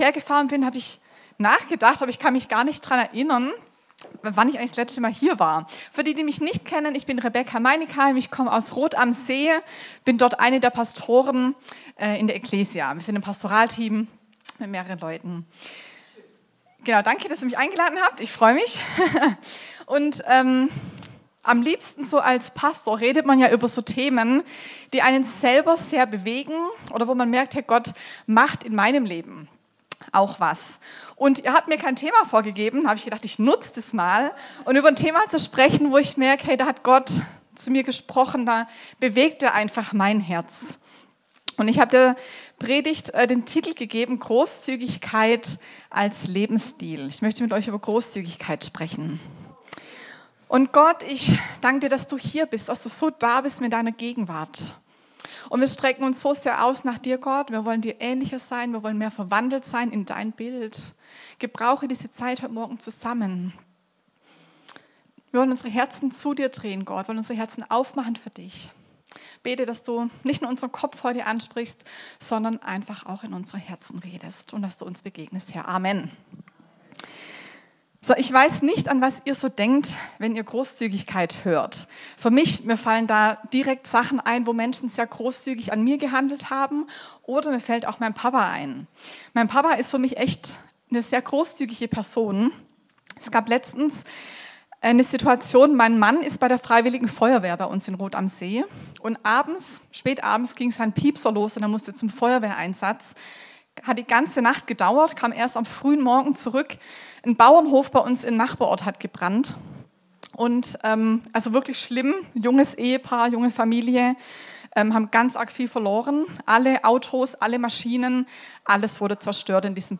Predigten | Willkommen